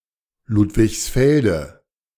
Ludwigsfelde (German pronunciation: [ˌluːtvɪçsˈfɛldə]
De-Ludwigsfelde.ogg.mp3